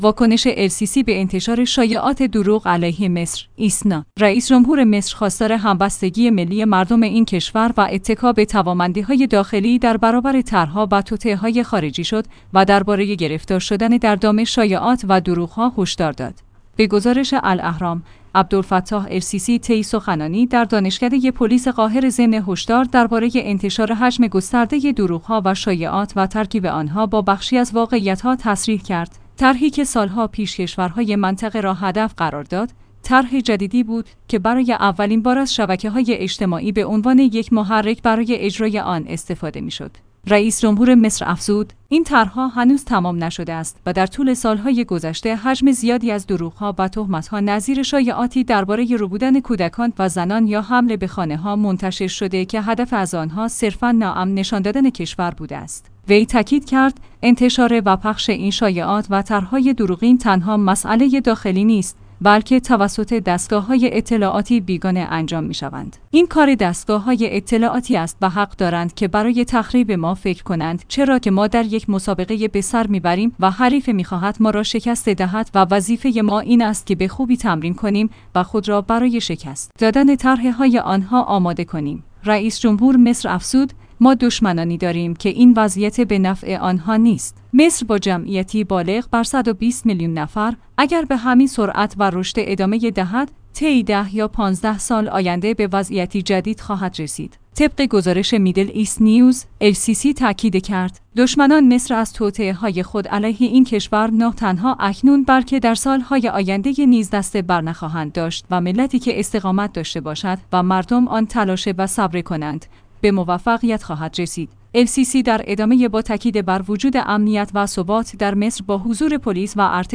ایسنا/ رئیس جمهور مصر خواستار همبستگی ملی مردم این کشور و اتکا به توامندی‌های داخلی در برابر طرح‌ها و توطئه‌های خارجی شد و درباره گرفتار شدن در دام شایعات و دروغ‌ها هشدار داد. به گزارش الاهرام، عبدالفتاح السیسی طی سخنانی در دانشکده پلیس قاهره ضمن هشدار درباره انتشار حجم گسترده دروغ‌ها و شایعات و ترک